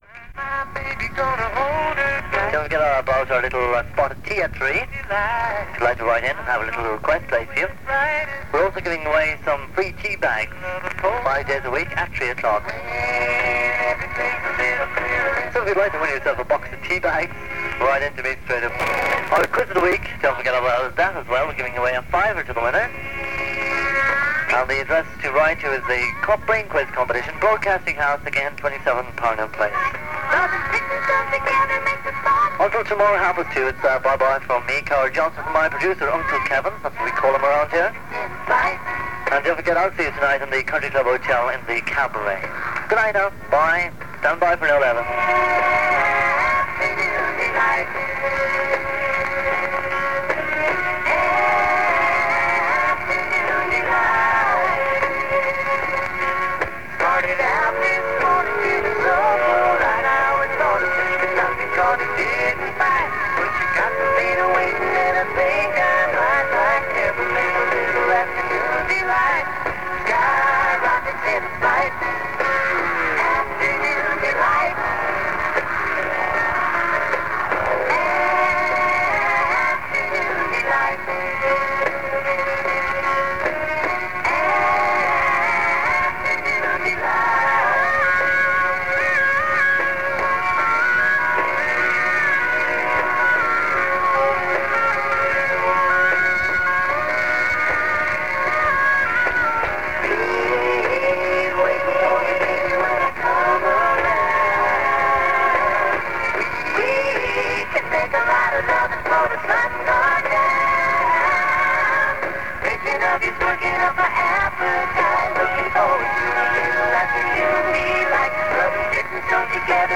This recording was made during the second week of broadcasting of the Cork pirate Radio City, that came on the air in September 1980.
Many DJs refer to their gigs in local hotels or nightclubs, which was common among pirate radio presenters anxious to supplement their income in the lean 1980s.